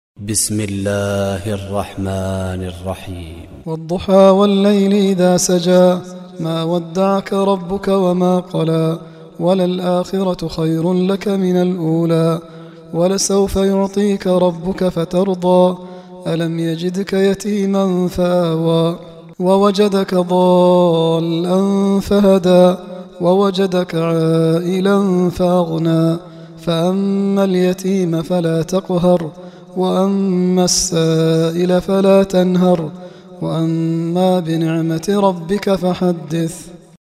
May Allah bless you Sheikh for your recitation.